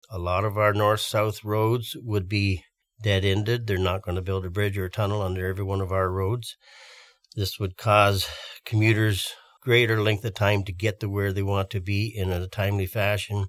In an interview with myFM, Mayor Claire Kennelly said the rail line would divide the township.